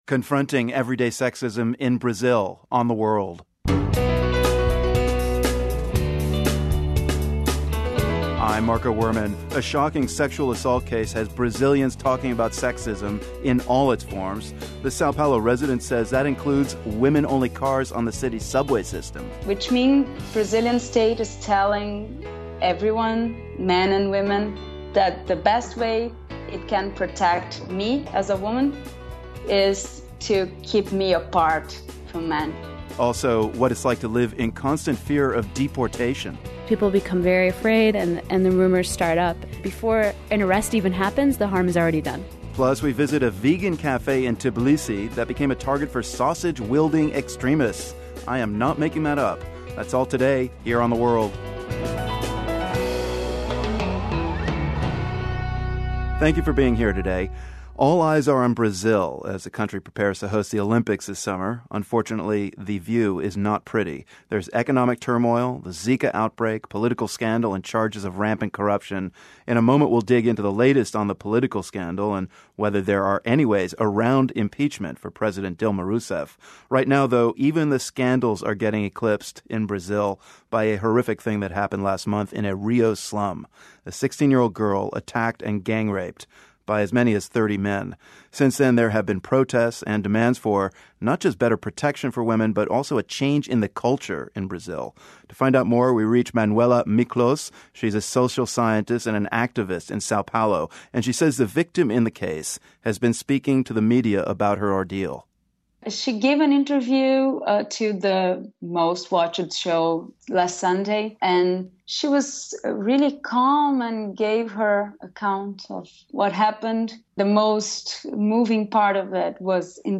Host Marco Werman speaks with an activist in Sao Paulo about the case, and about the everyday sexism and harassment that many Brazilian women face. The other big story in Brazil is the ongoing presidential impeachment saga.
Plus, Marco Werman has a conversation with Cuban trumpet player Arturo Sandoval.